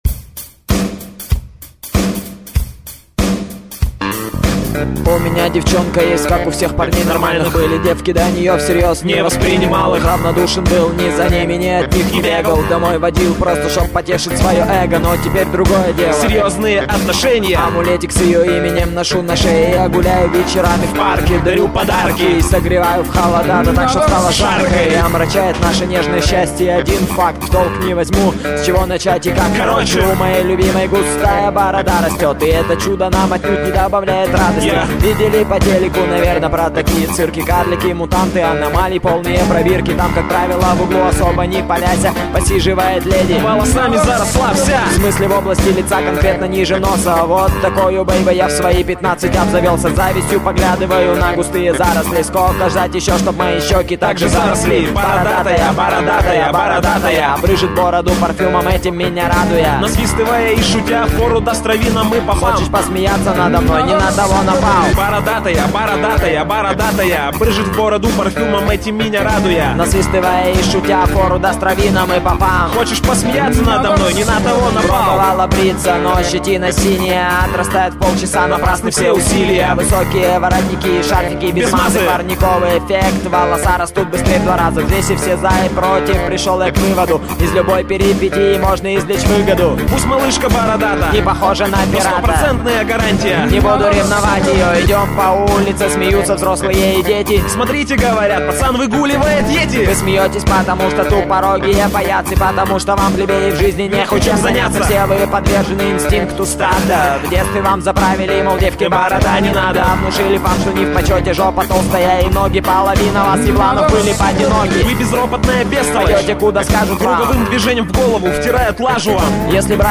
Файл в обменнике2 Myзыкa->Рэп и RnВ
Смешно,немного напоминает "мальчишник"